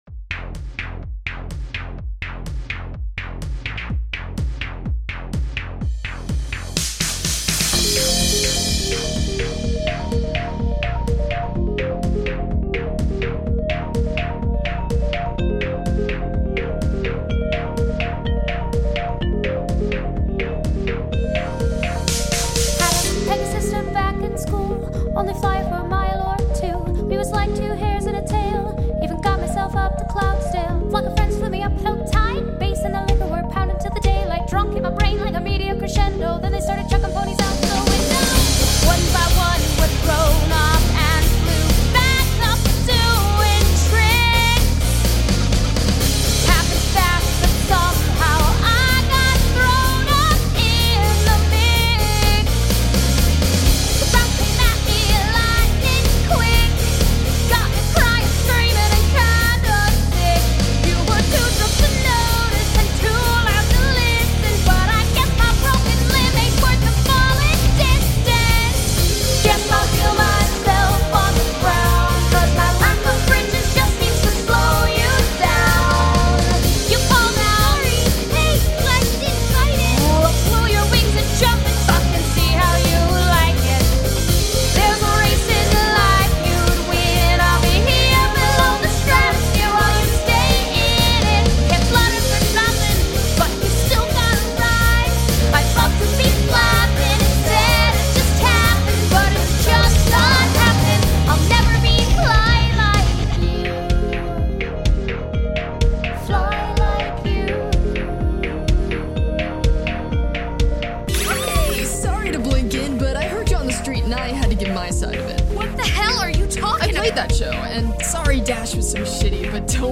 The 1980s version of our song
keyboard solos